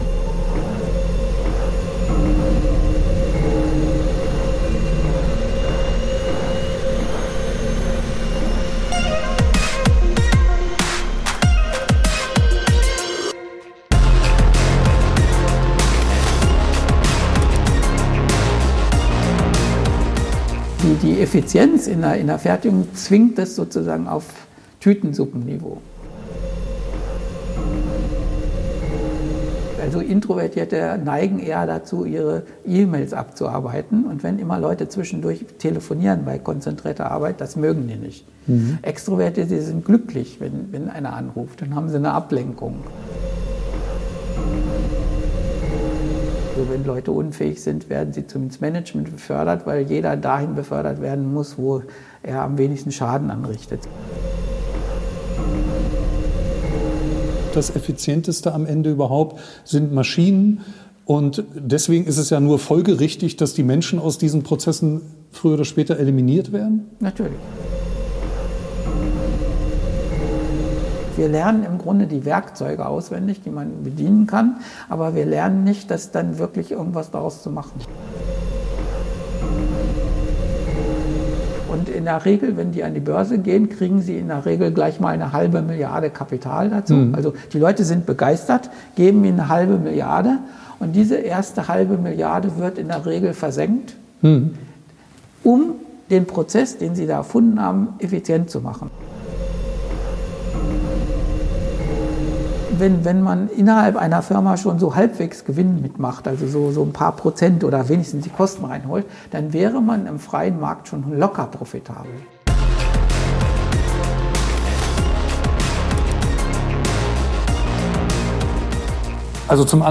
Es geht um Bildung, Management, Börsentips und wie von beiden gewohnt, um vieles mehr. Das Interview wurde im Frühjahr 2021 geführt.